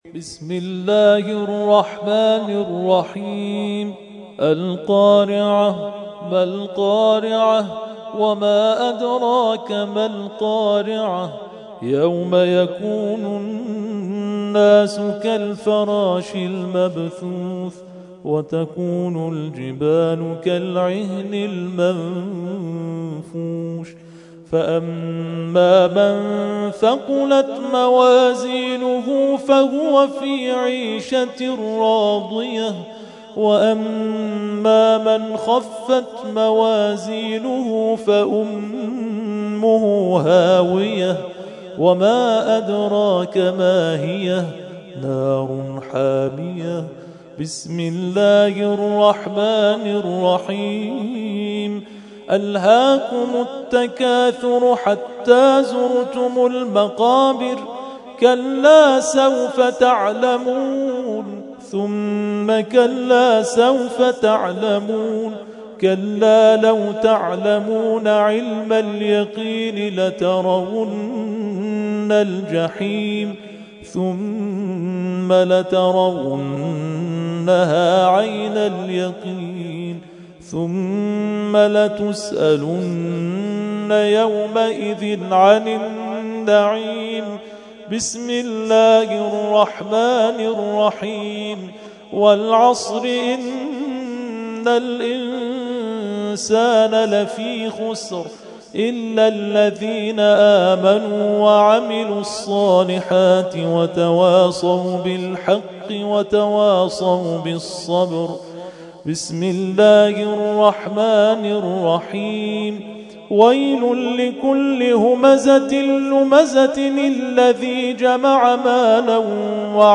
ترتیل خوانی جزء ۳۰ قرآن کریم در سال ۱۳۹۵